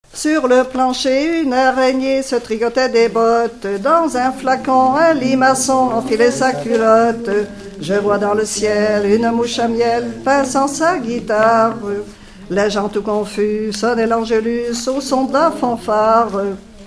enfantine : lettrée d'école
Catégorie Pièce musicale inédite